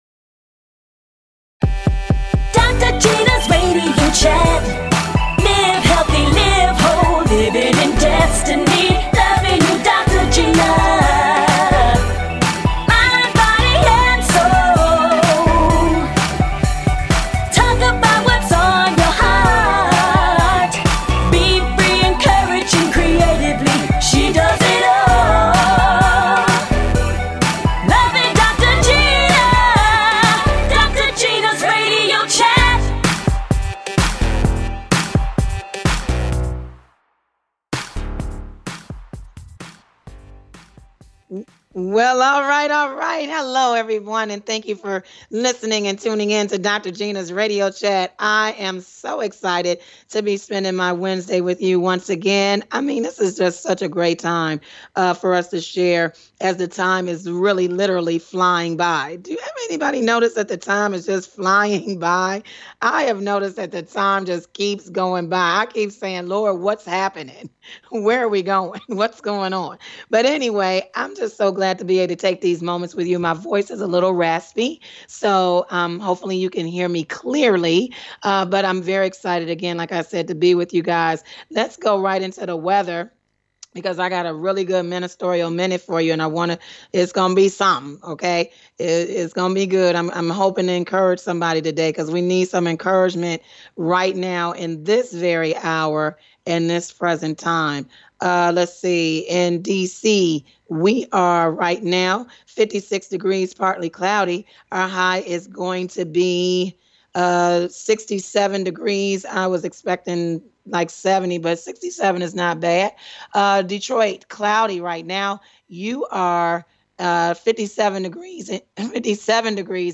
Talk Show Episode
Guests, R&B artist, CUPID